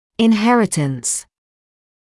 [ɪn’herɪtəns][ин’хэритэнс]наследственность; наследование